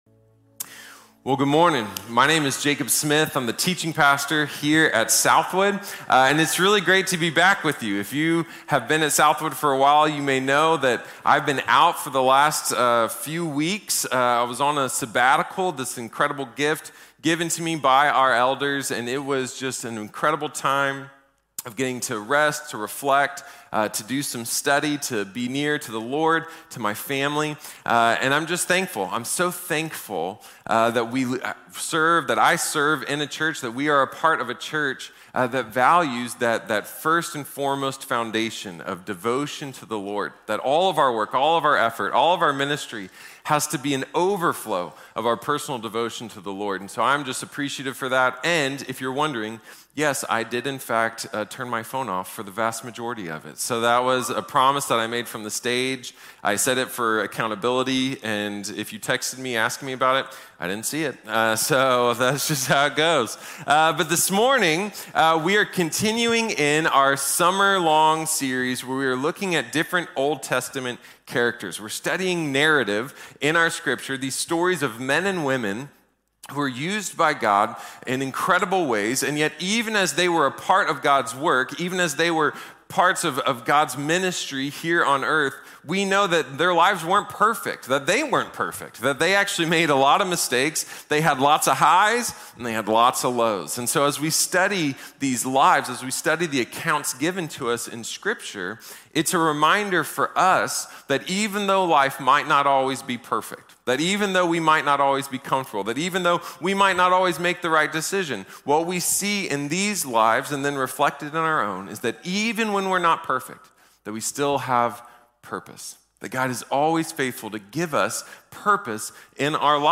Samson | Sermon | Grace Bible Church